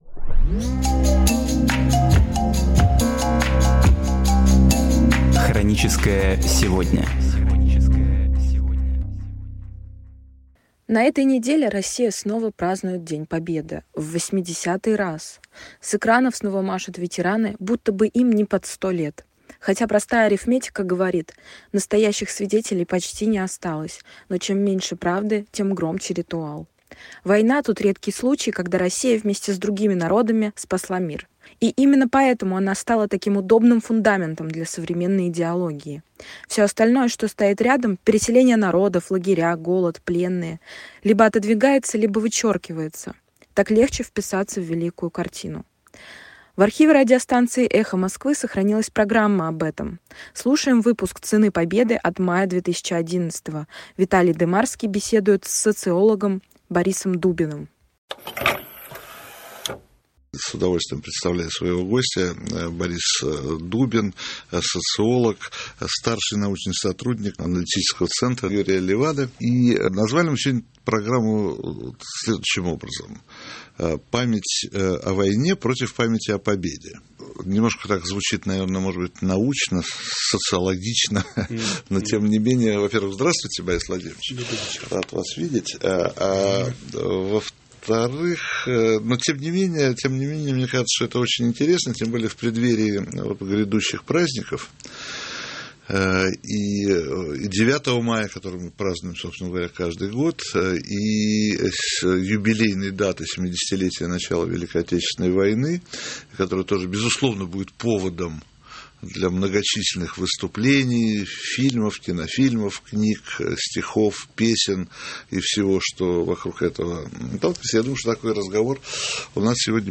В архиве радиостанции «Эхо Москвы» сохранилась программа об этом.
Виталий Дымарский беседует с социологом Борисом Дубиным.